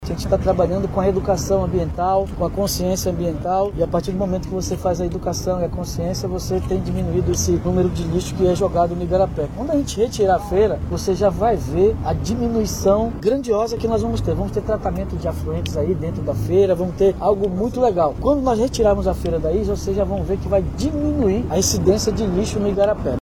Sobre o problema de acúmulo e descarte irregular de lixo, naquela área, Davi Almeida disse que medidas já estão sendo adotadas e serão intensificadas.